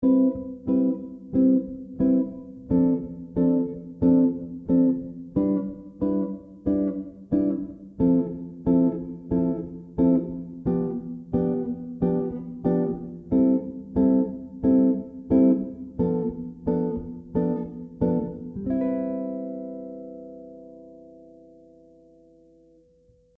JASON BECKER ARPEGGI IN TAPPING